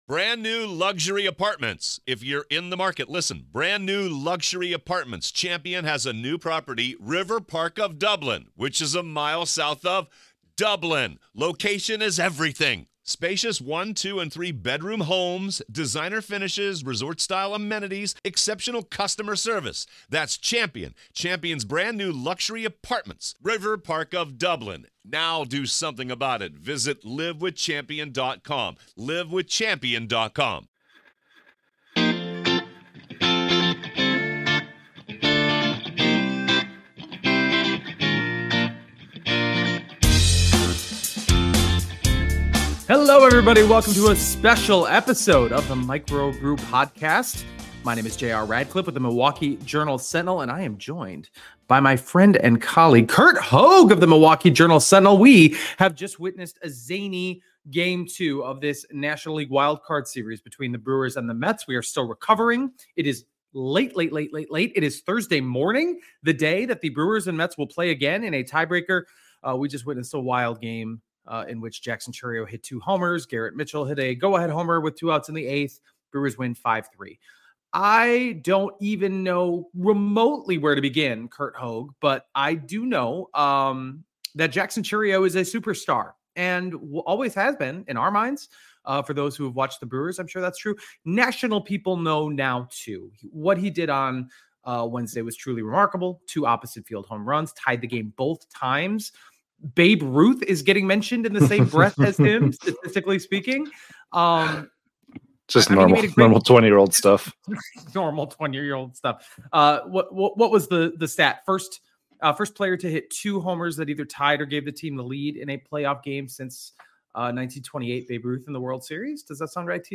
How should the Brewers approach Game 3? They discuss on this edition of the Microbrew podcast.